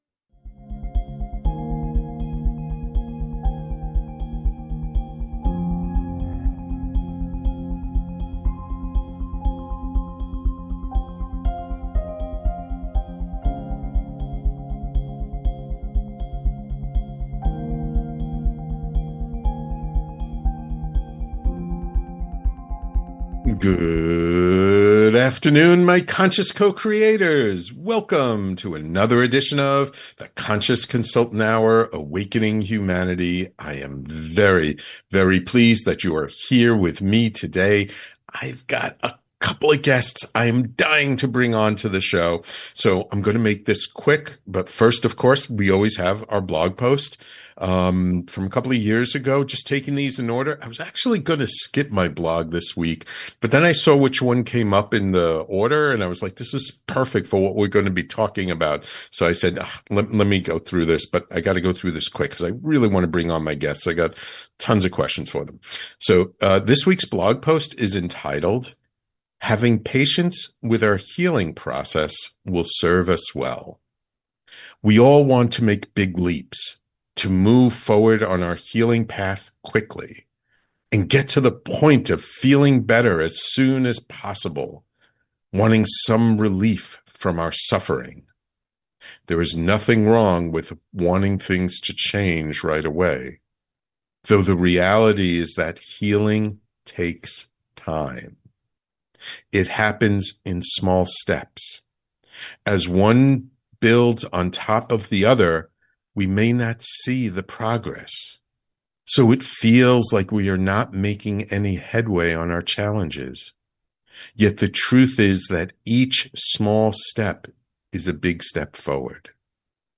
In this deep and fascinating conversation